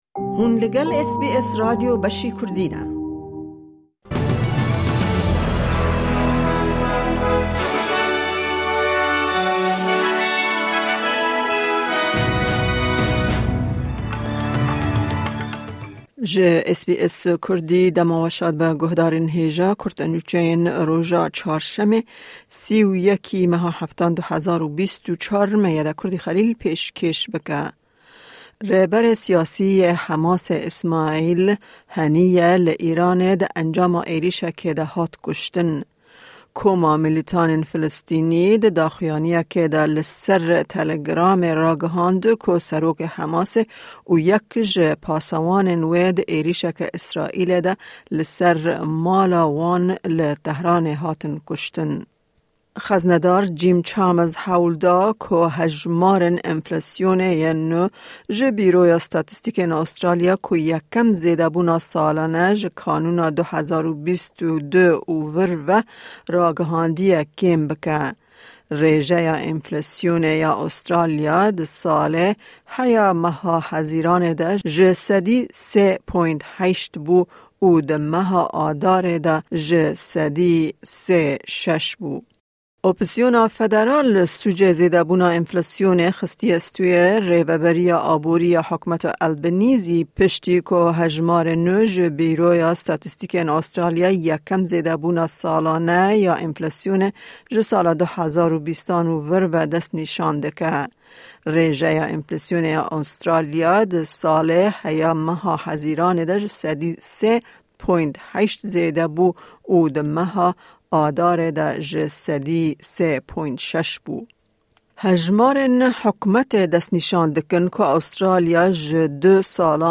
Kurte Nûçeyên roja Çarşemê 31î Tîrmeha 2024